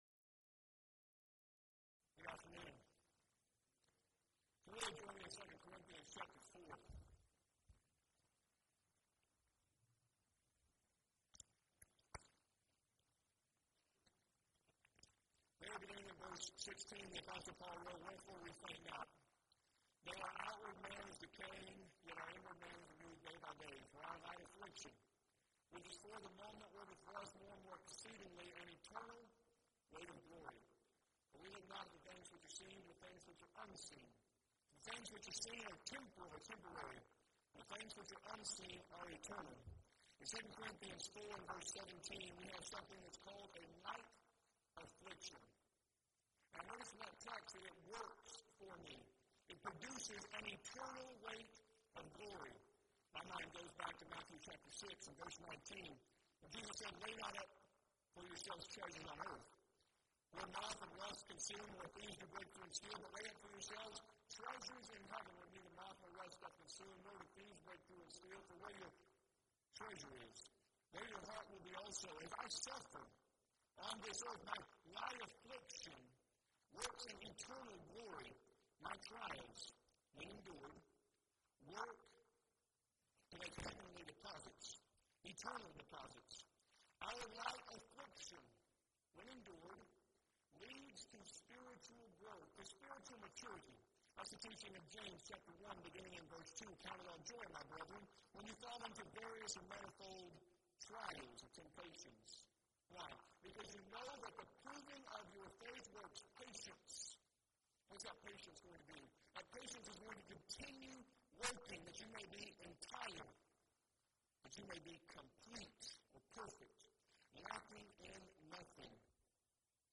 Event: 2nd Annual Arise Workshop
lecture